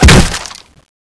Index of /server/sound/weapons/tfa_cso/speargun
wood2.wav